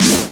Snare Drum 67-03.wav